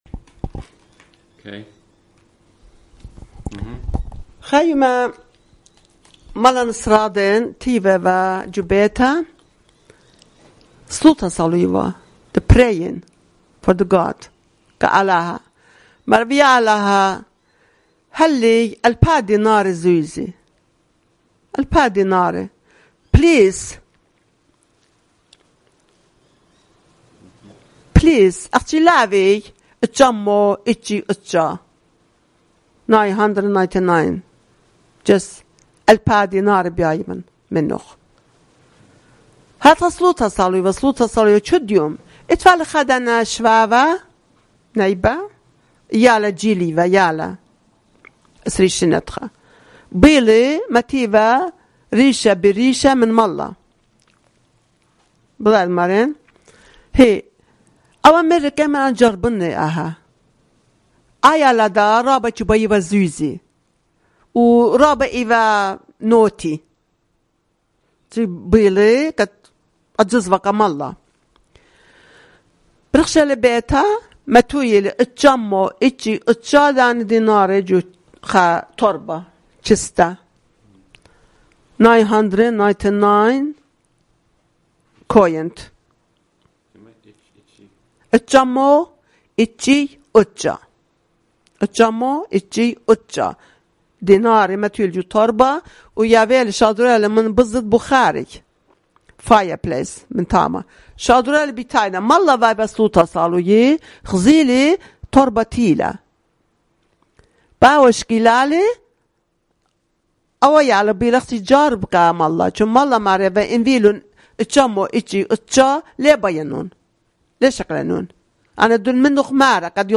Urmi, Christian: A Thousand Dinars